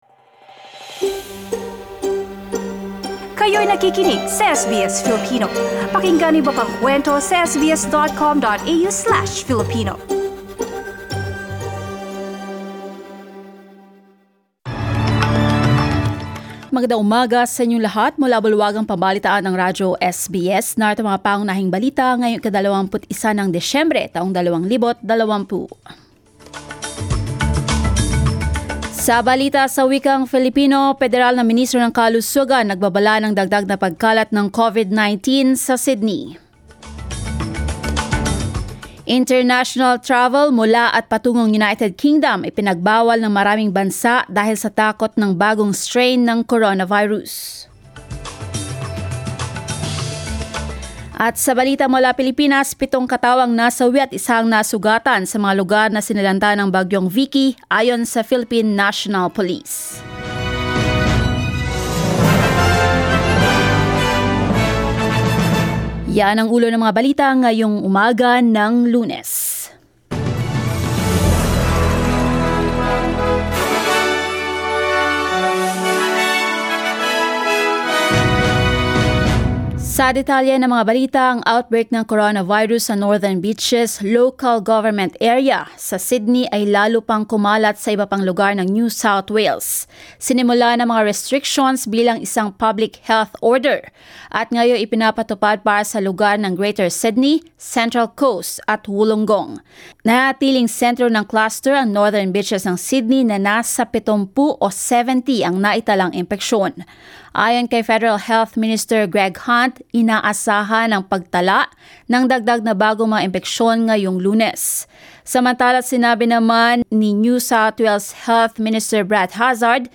SBS News in Filipino, Monday 21 December